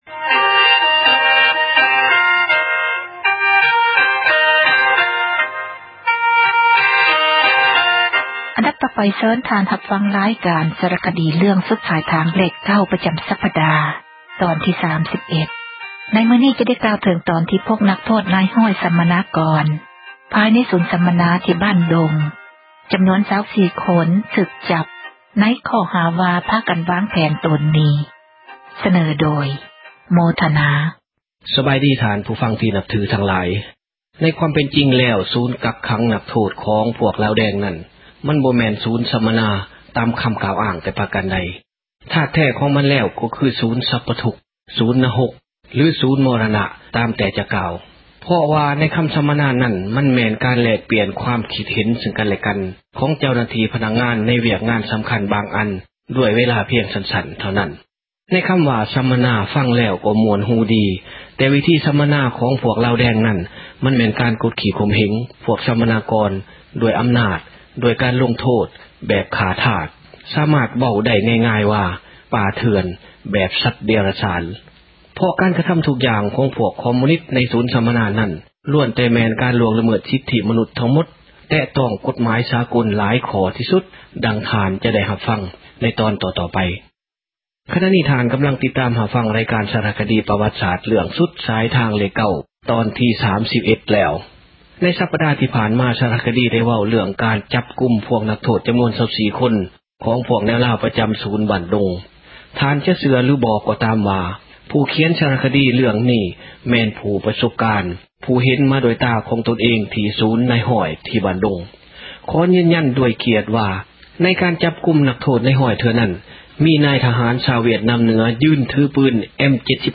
ເຊີນທ່ານຮັບຟັງ ຣາຍການ ສາຣະຄະດີເຣື້ອງ ”ສຸດສາຍທາງເລຂ 9” ປະຈຳສັປດາ ຕອນທີ 31. ໃນມື້ນີ້ ຈະໄດ້ກ່າວເຖິງຕອນ ທີ່ພວກນັກໂທດ ນາຍຮ້ອຍ ສັມມະນາກອນ ພາຍໃນສູນ ສັມມະນາທີ່ ”ບ້ານດົງ” ຈຳນວນ 24 ຄົນຖືກຈັບ ໃນຂໍ້ຫາວ່າ ພາກັນວ່າງແຜນ ໂຕນໜີ.